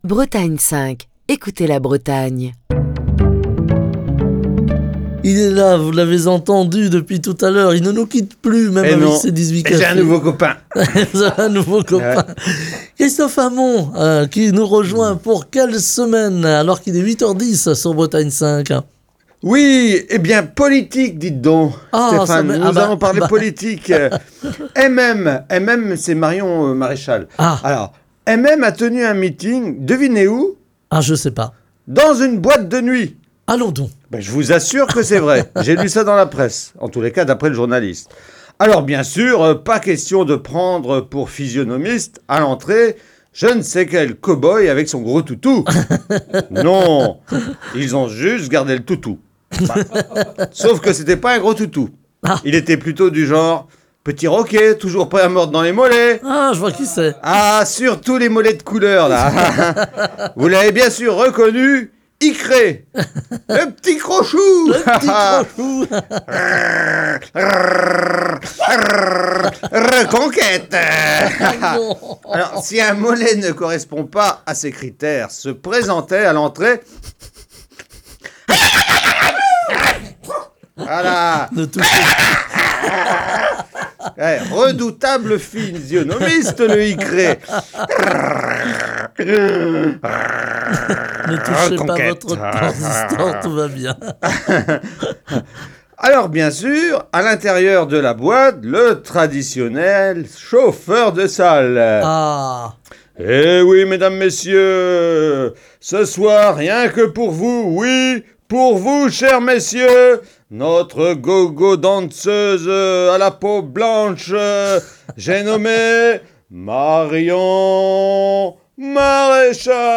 Il est important de préciser qu'aucun animal n'a été maltraité pour cette chronique... et tous les aboiements ont été réalisés à la bouche !